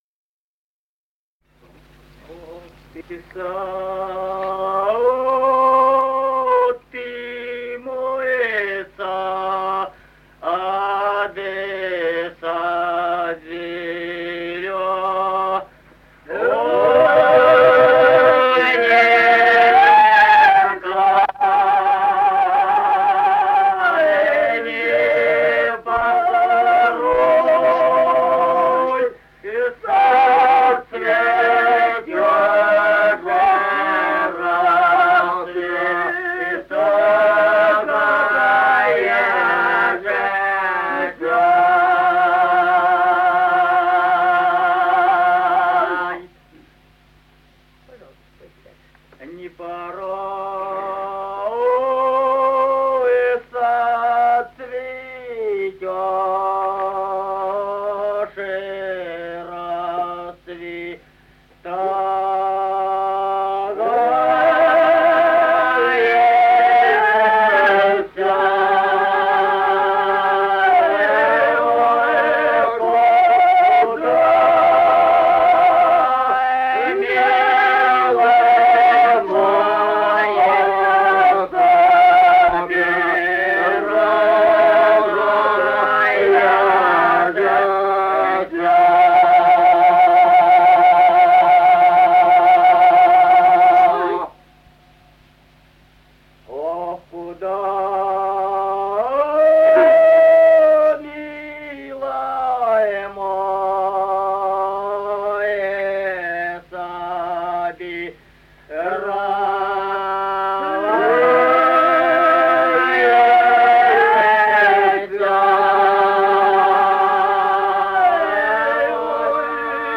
Музыкальный фольклор села Мишковка «Ох, ты сад, ты мой сад», лирическая.